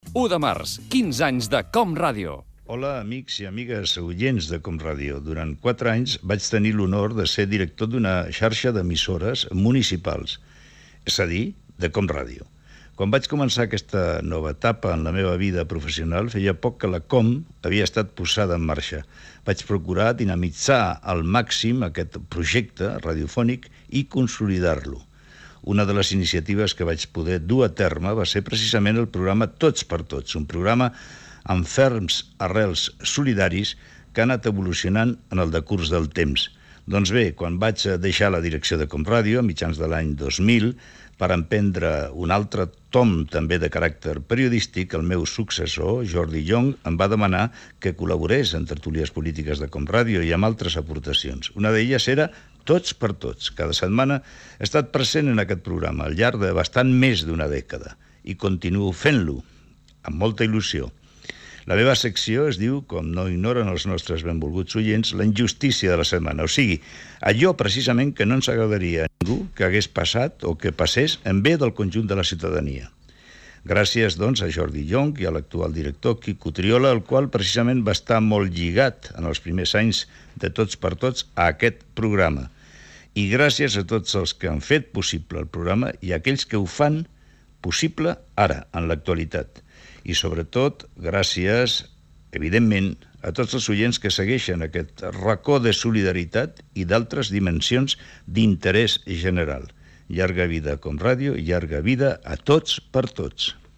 Amb motiu dels 15 anys de COM Ràdio Enric Sopena parla del seu pas com a Director General de l'emissora i de la seva col·laboració en el programa "Tots per tots"
Fragment extret de l'arxiu sonor de COM Ràdio